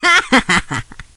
shelly_kill_05.ogg